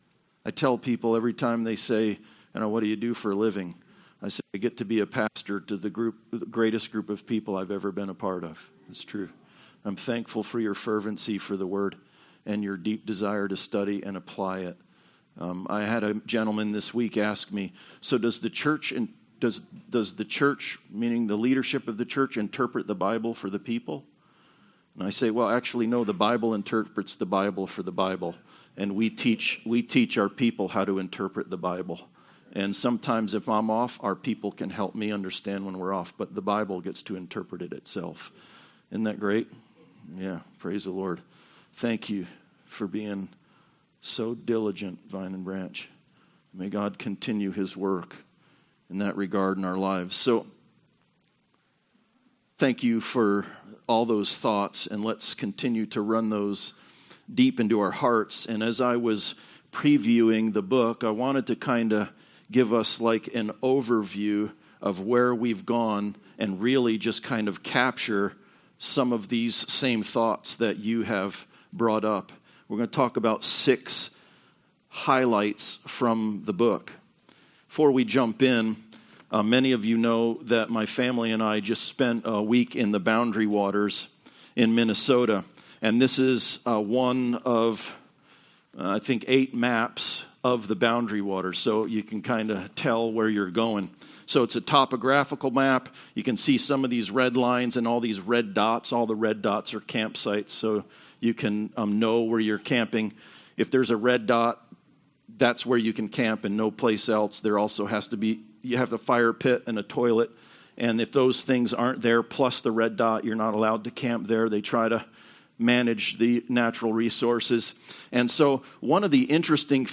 Nehemiah: True North - Vine and Branch Church in Nashville, Indiana